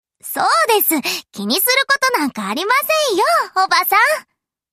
Sample Voice